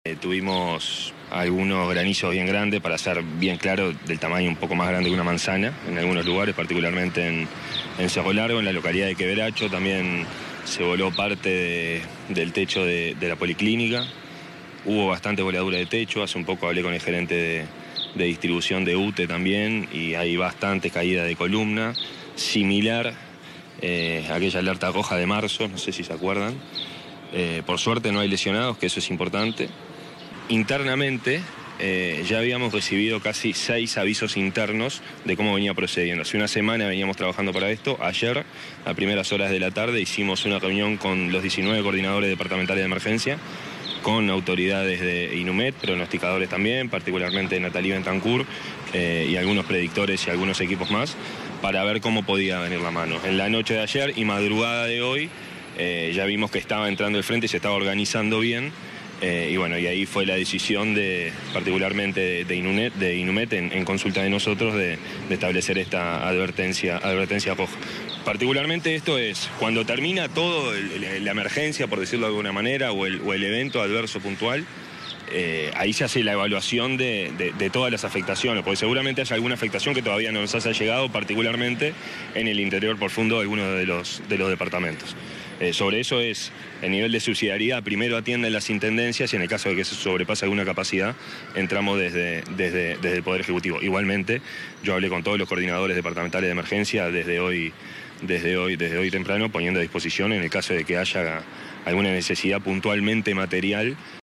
En Cerro Largo, se registró una granizada, con voladuras en parte de un techo de la antigua policlínica de Quebracho y además cayó arbolado en Noblía. El director del SINAE, Santiago Caramés explicó las principales afectaciones.